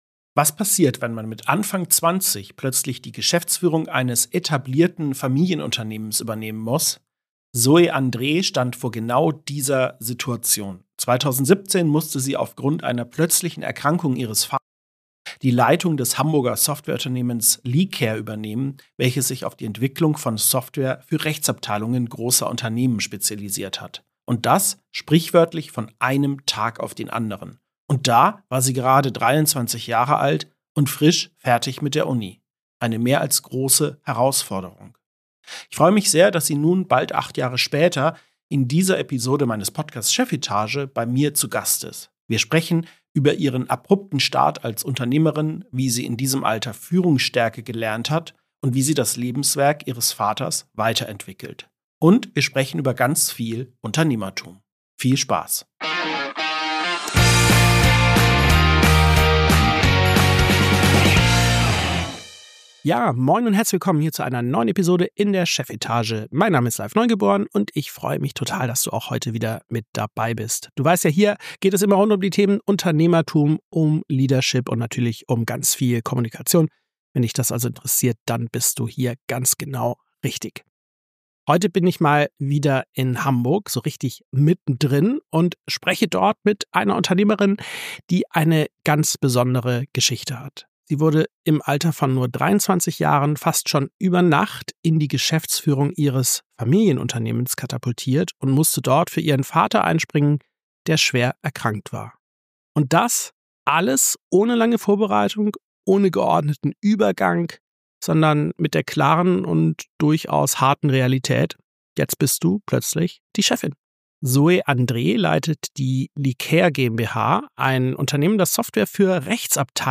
70 ~ Chefetage - CEOs, Unternehmer und Führungskräfte im Gespräch Podcast